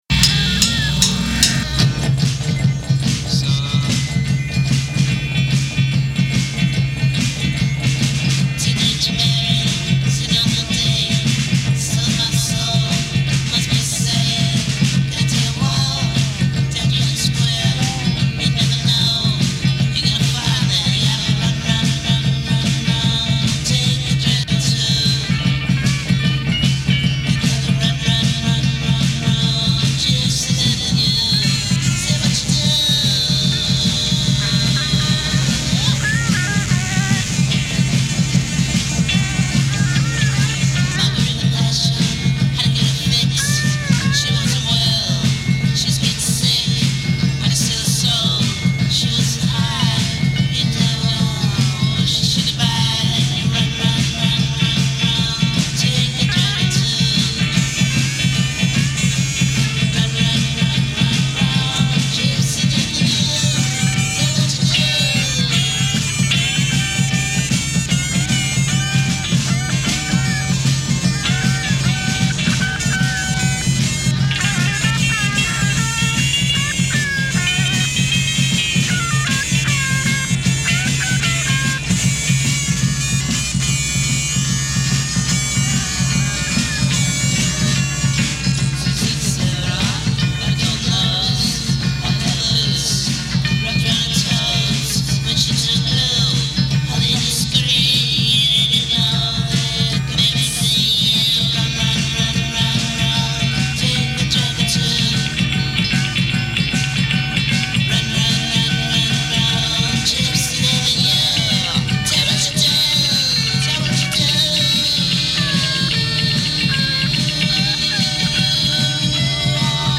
cover